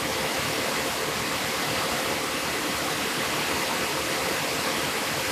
IS-95B carrier signal; 32khz bandwidth with AMAmplitude Modulation demodulation.